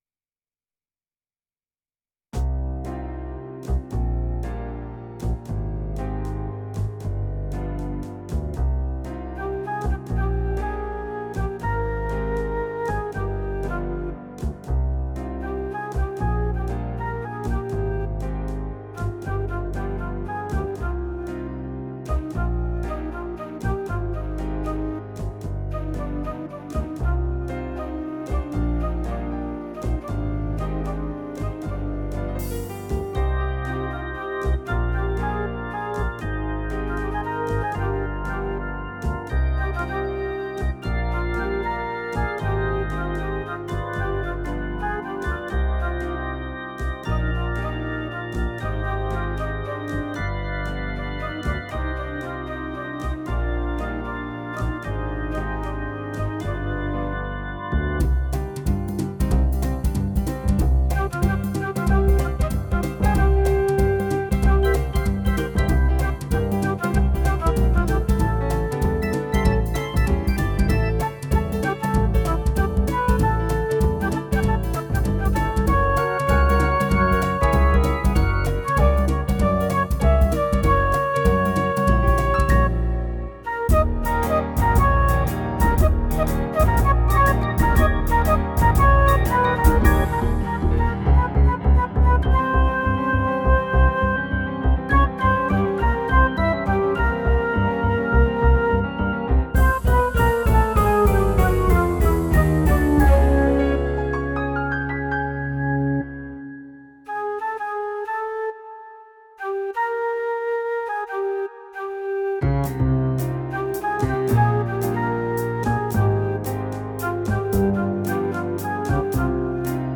multi-track instrumental version